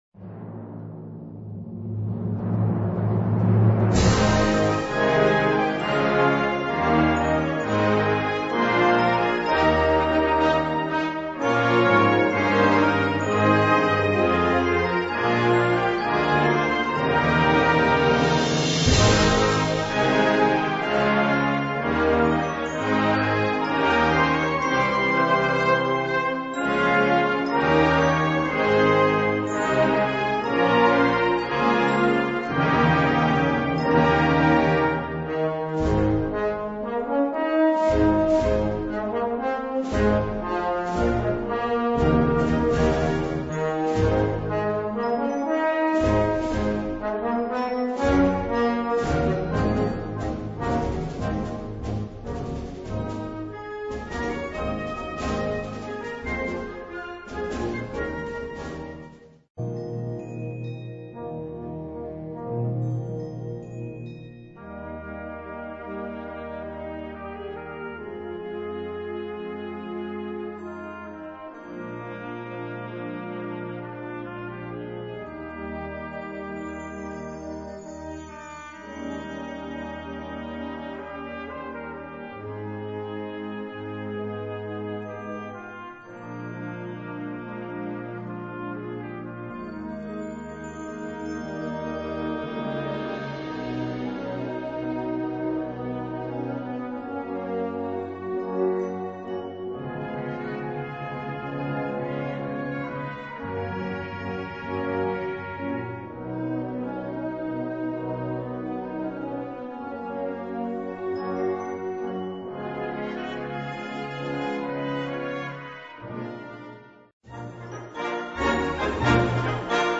Kategorie Blasorchester/HaFaBra
Unterkategorie Zeitgenössische Musik (1945-heute)